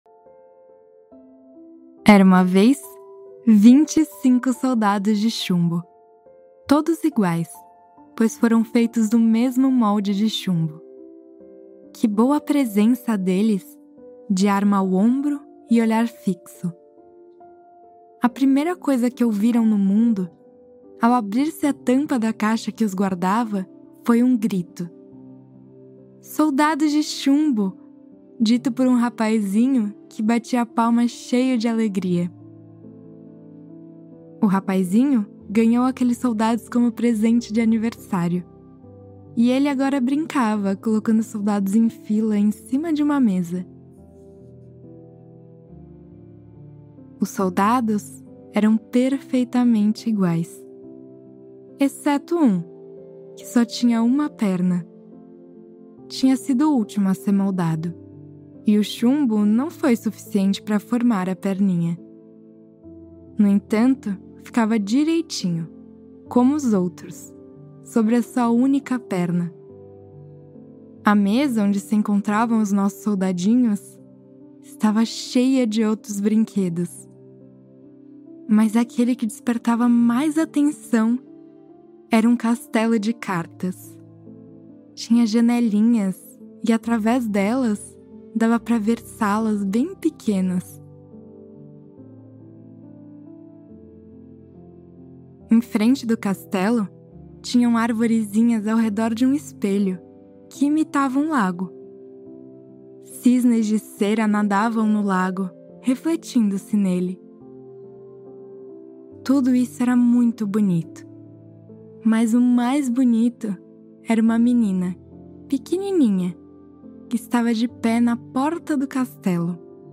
Before you sink into the quiet moments of Sleep Deeply, know this first: all advertisements are placed gently at the very beginning of each episode so nothing interrupts your rest, your breath, or the fragile moment when your mind finally loosens its grip.